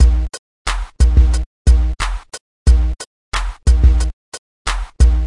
Intense Piano
描述：vst: Addictive Keys
标签： 150 bpm Hip Hop Loops Piano Loops 1.08 MB wav Key : Unknown
声道立体声